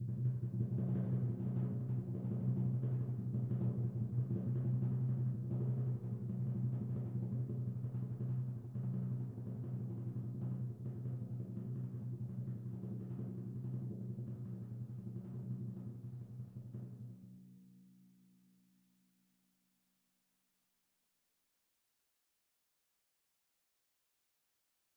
Timpani2_Roll_v3_rr1_Sum.wav